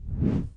咻咻咻的空气效果 " 06275导弹飞过
描述：通过导弹或火箭加工的声音
标签： 运动 飞掠 传球 旋风 导弹 woosh 火箭 传递由
声道立体声